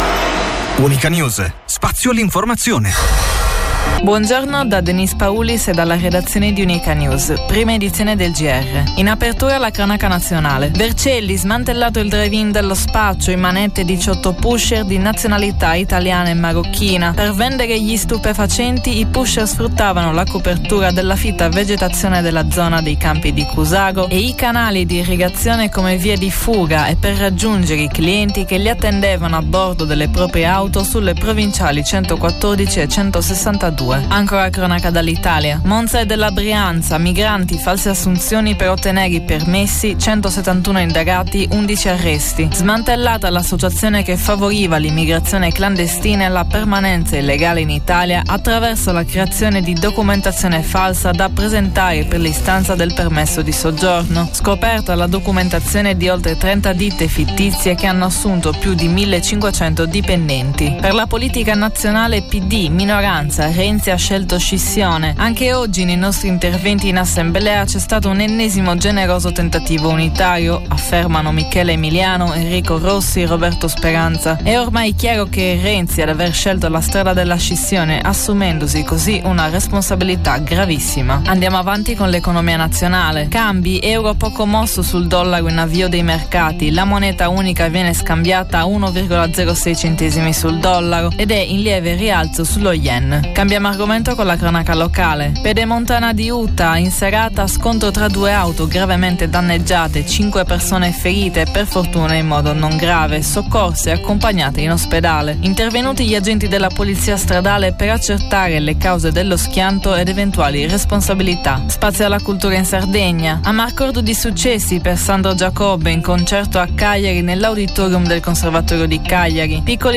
Quattro appuntamenti quotidiani dal lunedì al venerdì alle ore 10, 12, 17 e 19 in onda su Unica Radio.